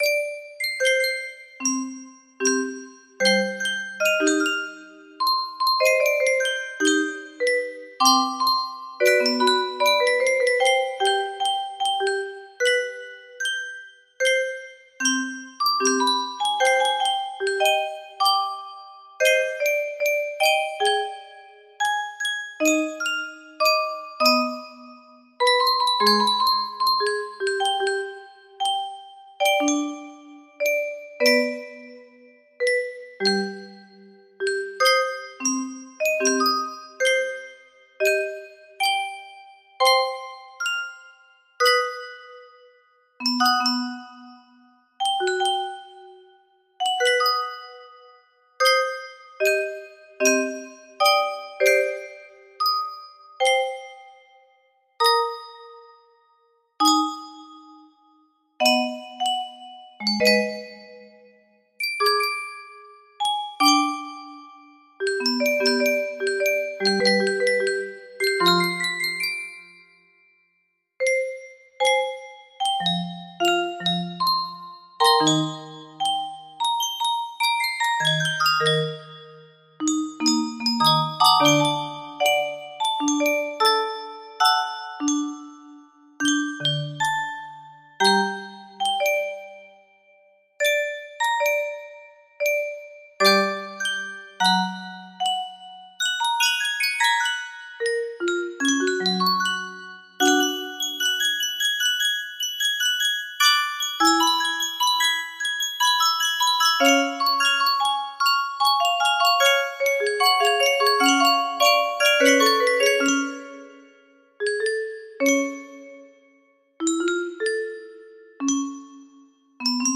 The Classical Collage Concerto music box melody
Full range 60